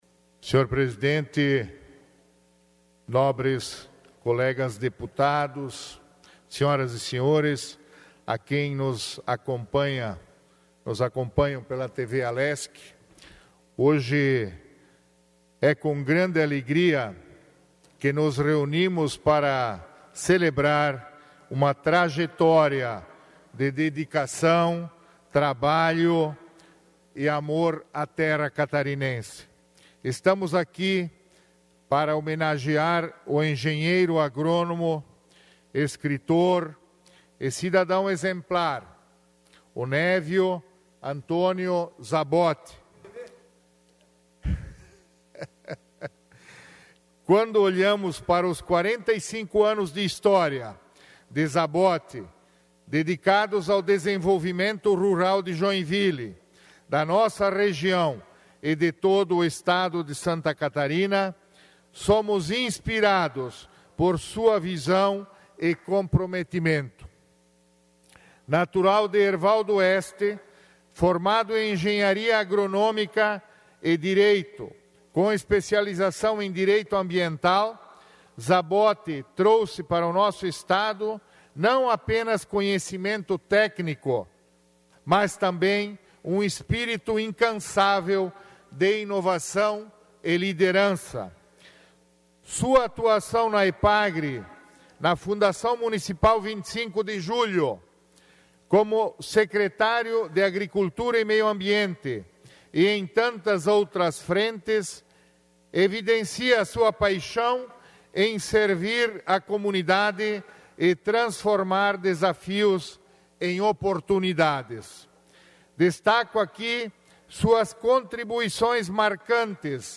Pronunciamentos da sessão ordinária desta quinta-feira (12)